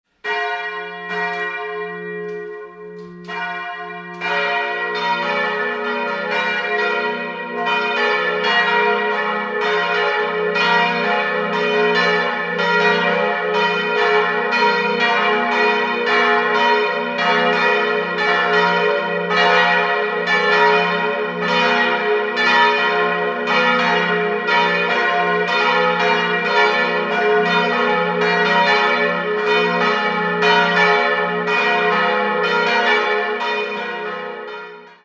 Im hellen, freundlichen Inneren eine für die Gegend typische Barockausstattung mit drei Altären. 3-stimmiges TeDeum-Geläute: f'-as'-b' Die beiden größeren Glocken wurden 1951 von Johann Hahn in Landshut gegossen, die kleine stammt aus dem Jahr 1972 von Rudolf Perner in Passau.